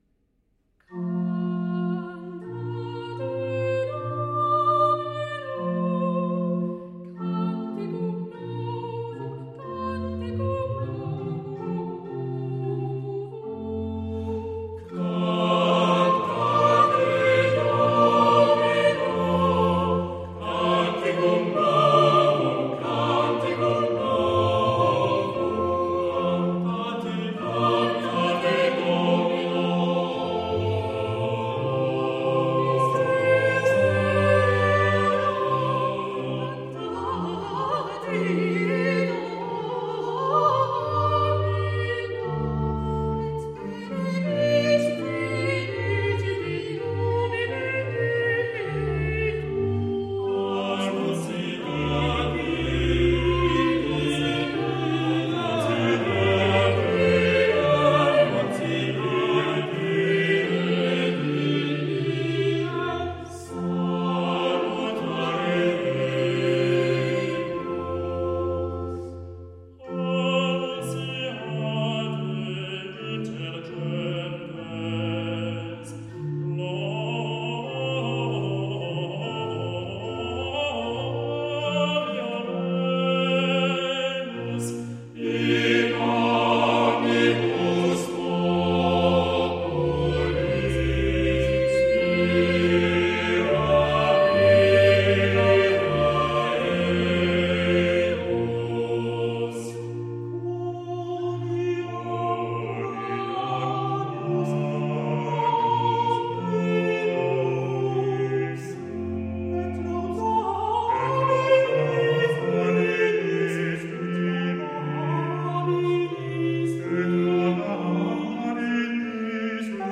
motet a quatre voix et continuo